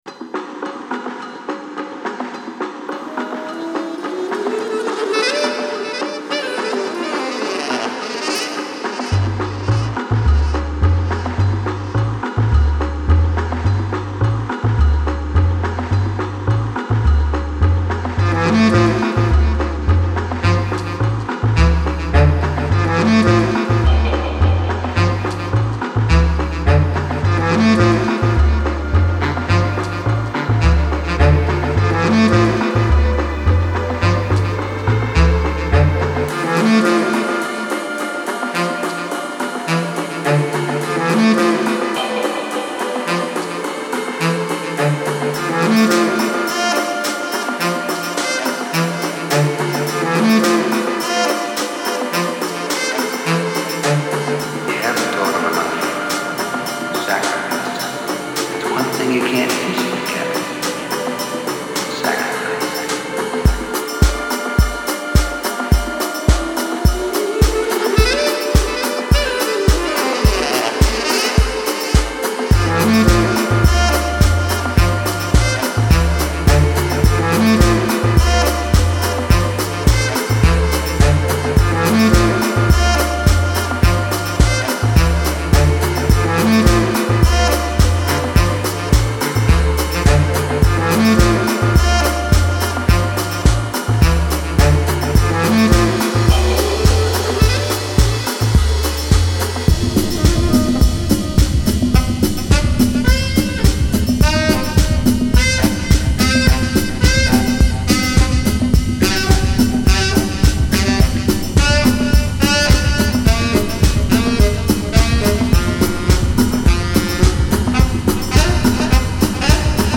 Genre: Downtempo, Experimental, Ambient.